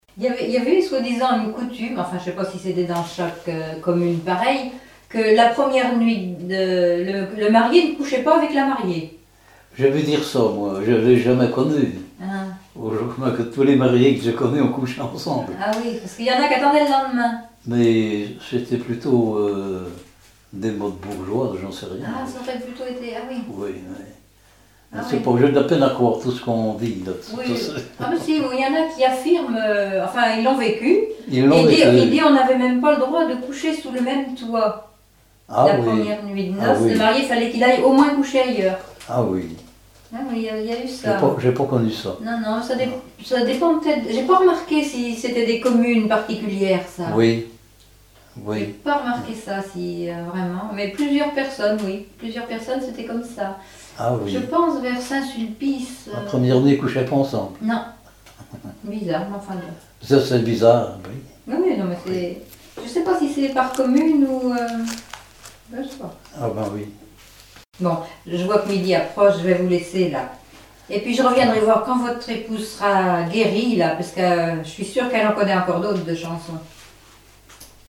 Enquête Arexcpo en Vendée-Pays Sud-Vendée
Catégorie Témoignage